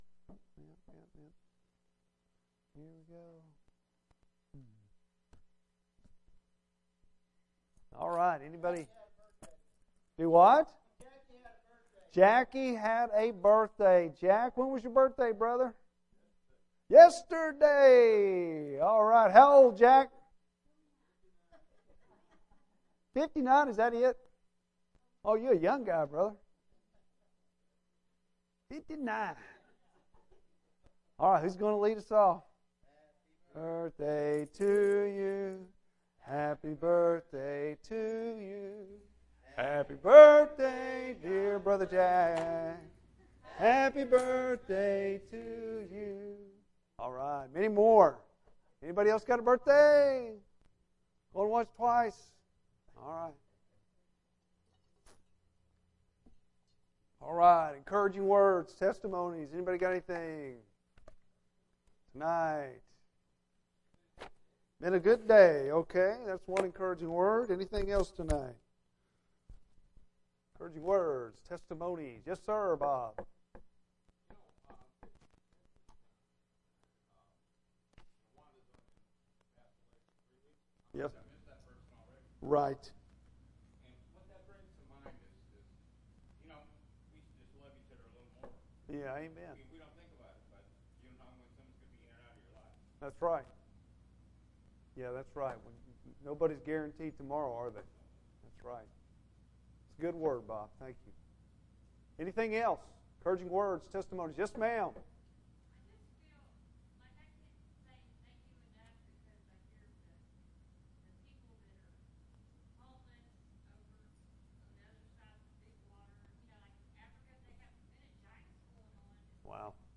Bible Text: Genesis 50:22-26 | Preacher